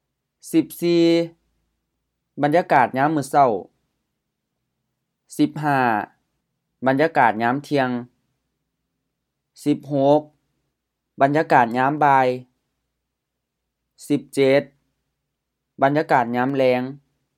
บันญากาด ban-ya:-ga:t M-HR-LF บรรยากาศ atmosphere
ญาม ɲa:m HR ยาม 1. period of time {ญามมื้อเซ้า = morning} {ญามเที่ยง = noon}